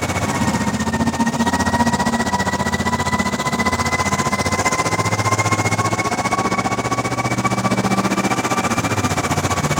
rocket_mouv.wav